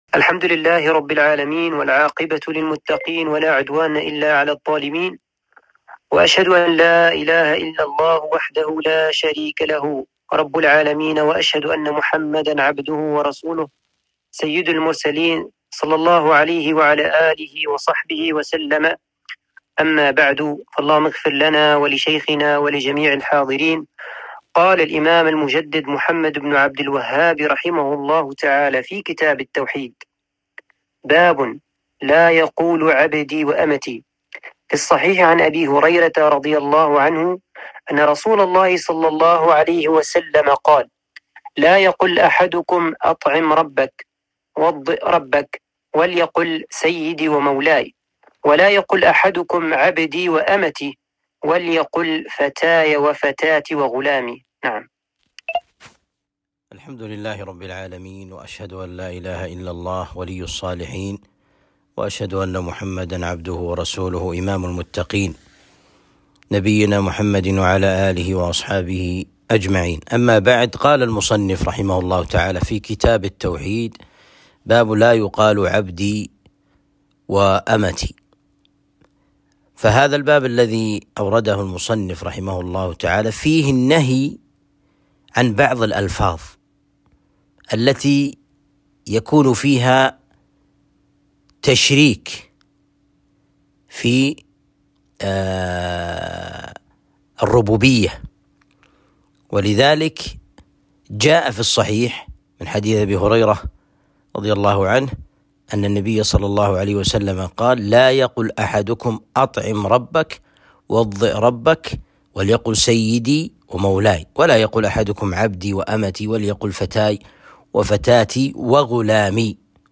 درس شرح كتاب التوحيد (49)